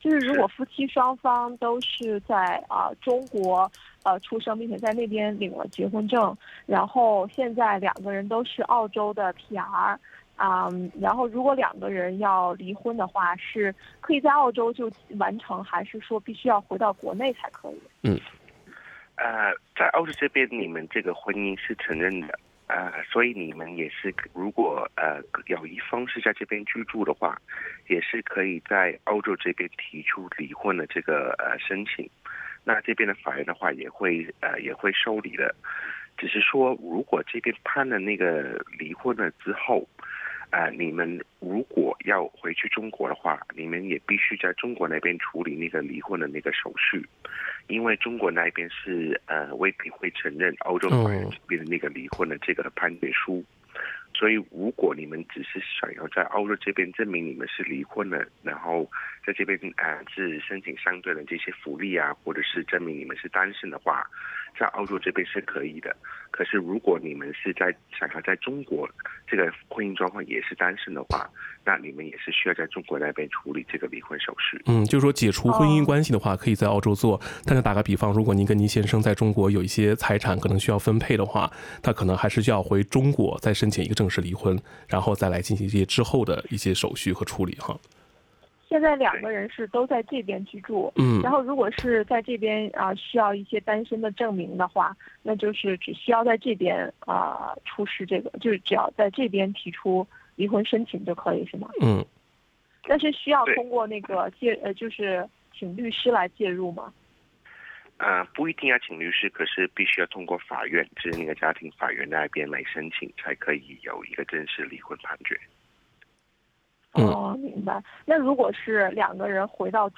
《现场说法》听众热线节目逢周二上午8点30分至9点播出。